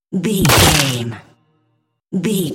Dramatic hit deep wood
Sound Effects
heavy
intense
dark
aggressive
hits